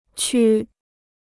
区 (qū) Free Chinese Dictionary